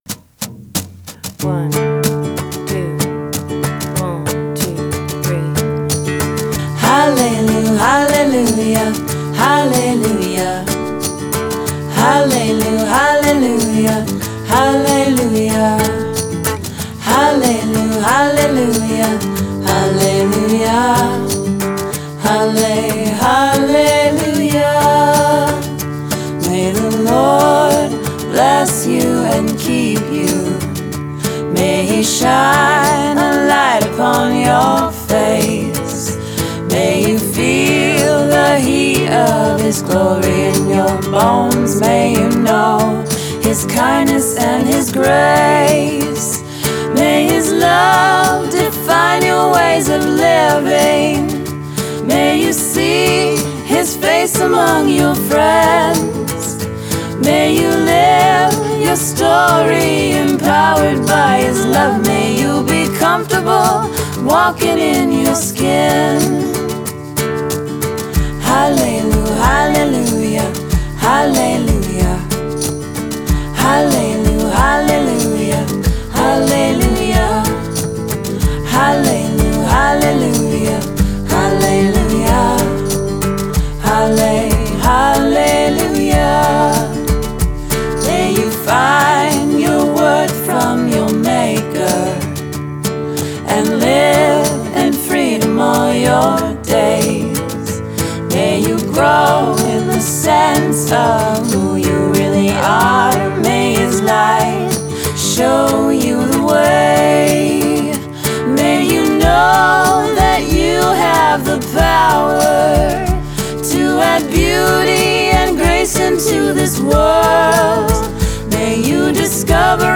03-benediction.m4a